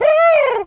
pgs/Assets/Audio/Animal_Impersonations/pigeon_call_angry_04.wav at master
pigeon_call_angry_04.wav